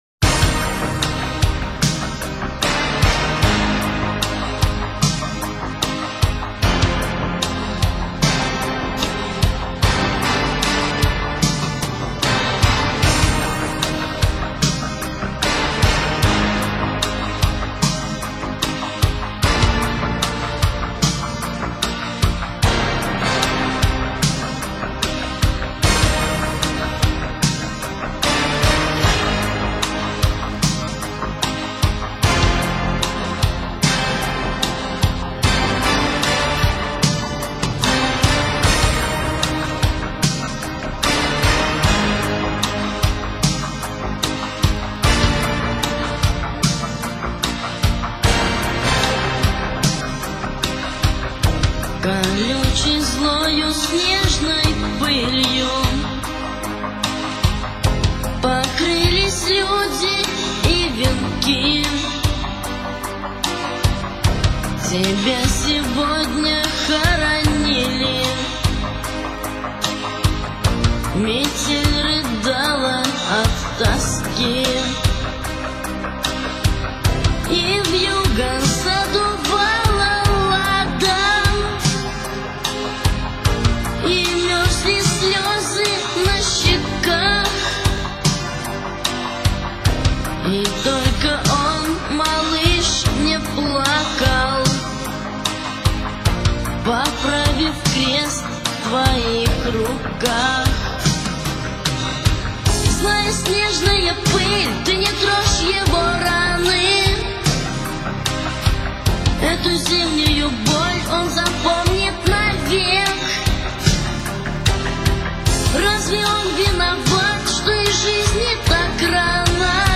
Очень грустная,,,капец,,,(((